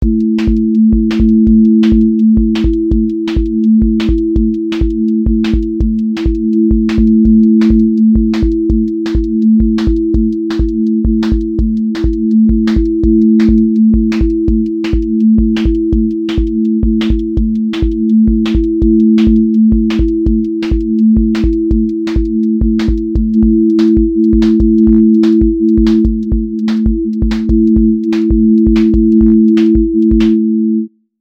QA Listening Test drum-and-bass Template: dnb_break_pressure
• voice_kick_808
• voice_snare_boom_bap
• voice_hat_rimshot
• voice_sub_pulse
• fx_space_haze_light
Neo-soul x drum-and-bass with lush chords, live pocket, warm body, drifting atmosphere, and clean switchups